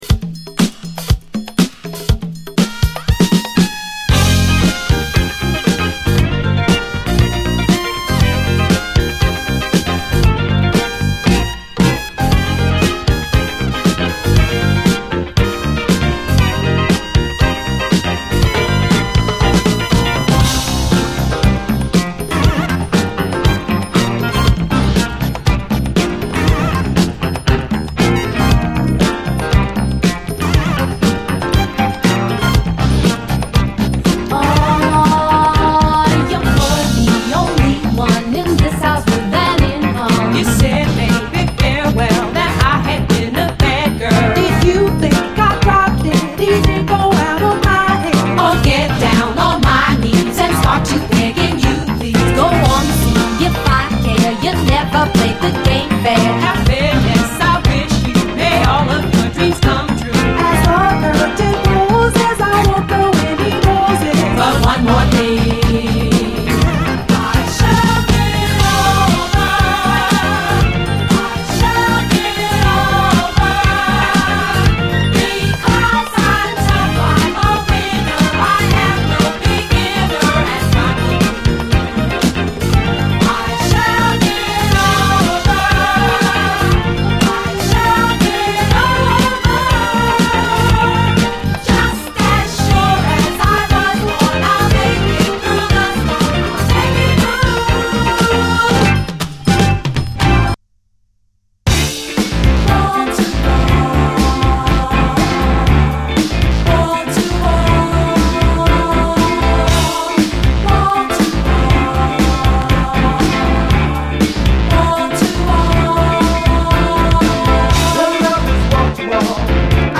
キャッチーなシンセ・ブギー・ソウル
ブラジリアンなインスト
アルバム通してお洒落なシンセ・ソウル！